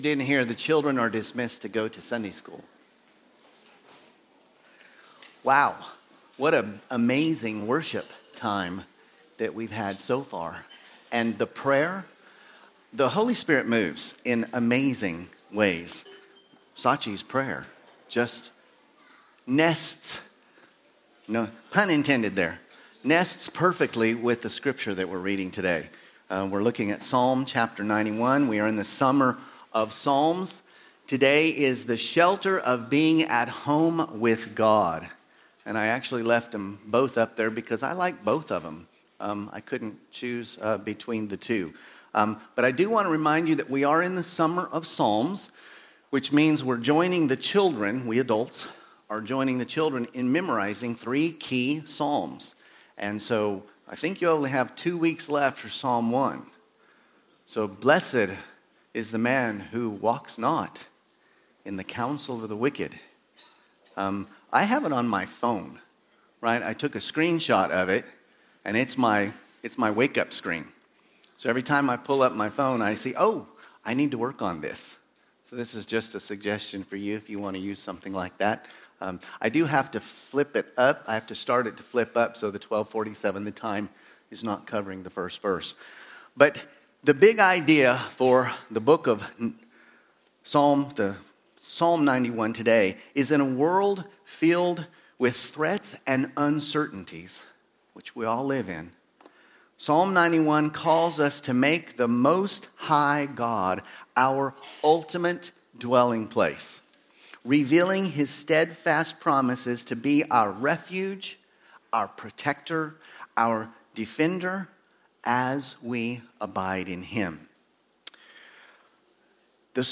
A message from the series "Sunday Service."